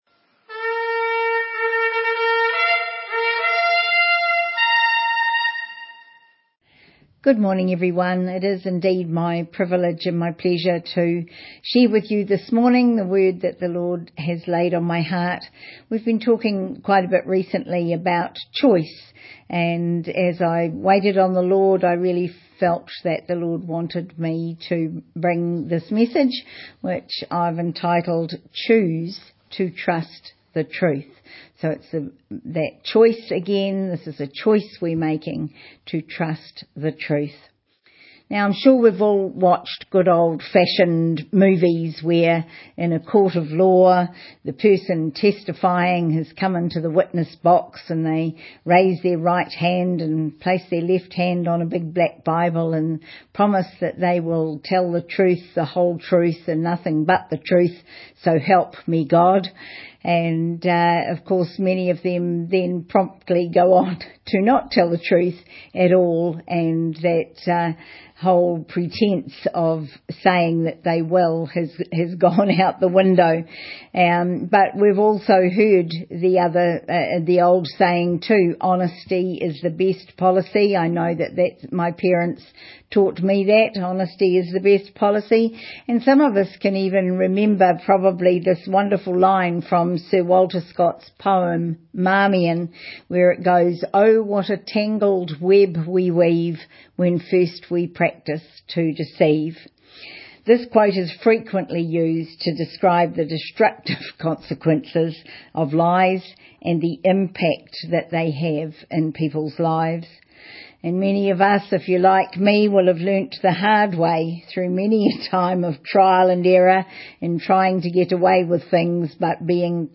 Online Sunday Morning Service 2nd March, 2025 Slides from this service are below.